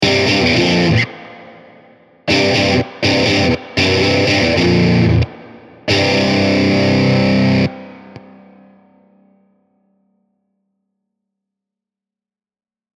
Ich habe nur Werks-Presets verwendet, außer etwas Hall und dem t-Racks Brickwall Limiter als Übersteuerungsschutz wurden keine Effekte verwendet.
Zuerst ein kurzes Riff, das auf Powerchords beruht.
7. Tiny Terror Preset „Metal Terror“
07_riff_tinyterror_metalterror.mp3